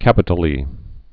(kăpĭ-tl-ē)